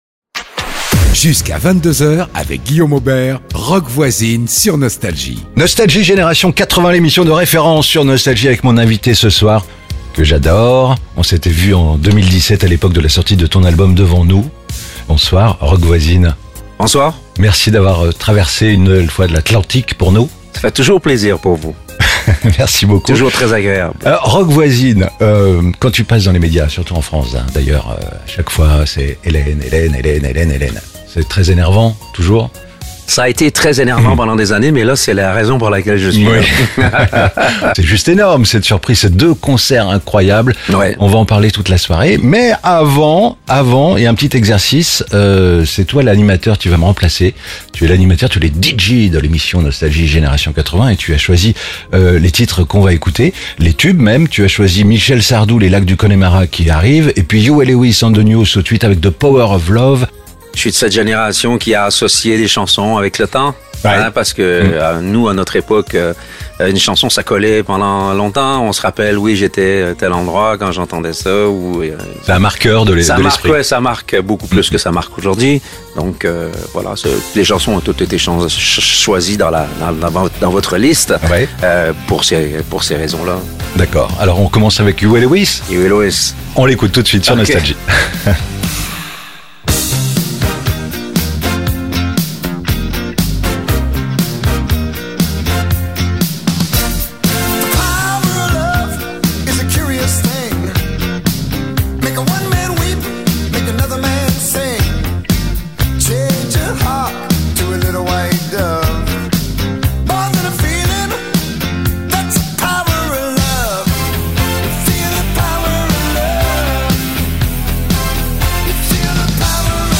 Vendredi 15 décembre, Roch Voisine était l'invité exceptionnel de Nostalgie Génération 80. L'artiste se prépare à célébrer les 35 ans de son tube emblématique "Hélène" avec deux concerts prévus en avril 2024 à Bruxelles et à Paris.